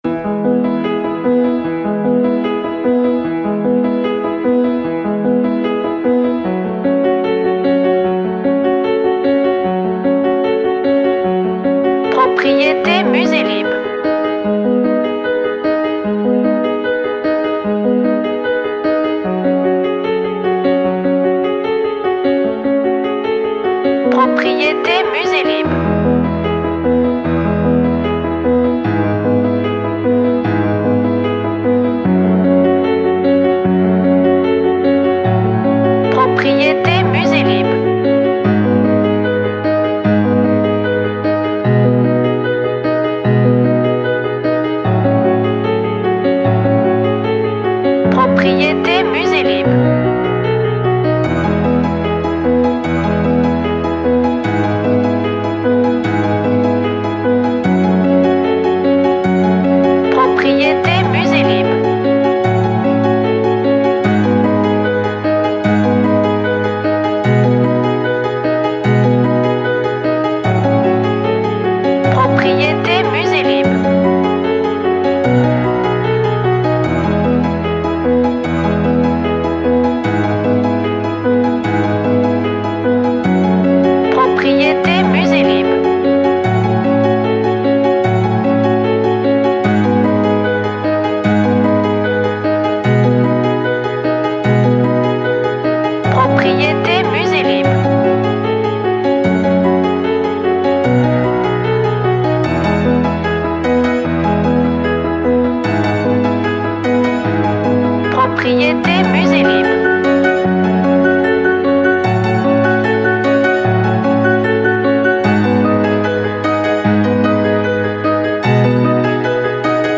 Douce mélodie de piano qui s'enrichit au fil du morceau
BPM Moyen